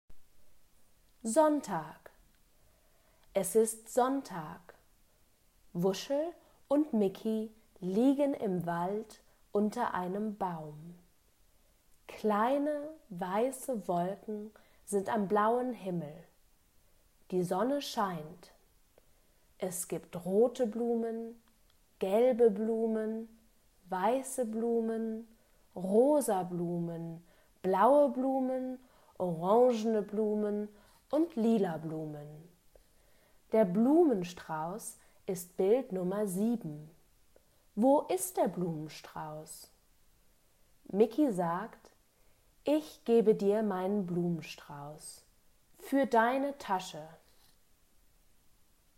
Gelesener Text: Begleitheft p.43(MP3, 784 KB)